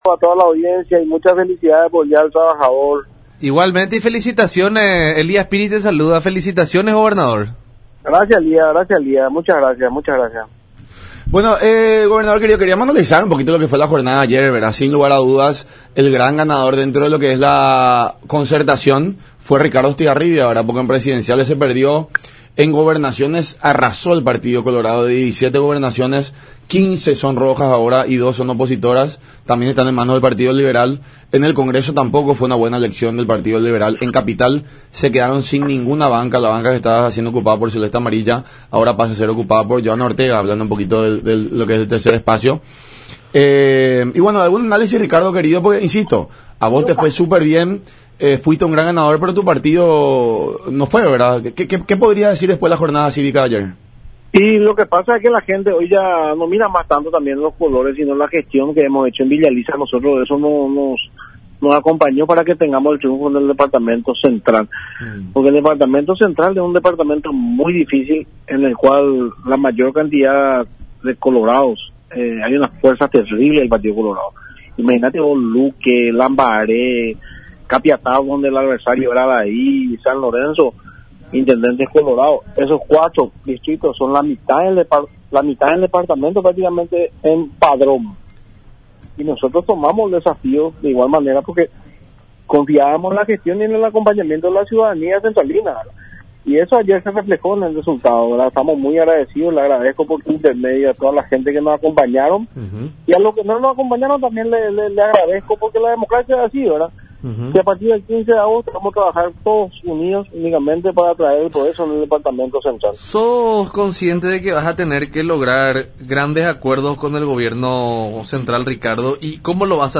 “Efraín debe darse cuenta de que tiene que dar un paso al costado”, expuso Estigarribia en conversación con La Unión Hace La Fuerza a través de Unión TV y radio La Unión, en alusión al titular del PLRA, que perdió por tercera ocasión consecutiva unas elecciones presidenciales (2013, 2018 y 2023).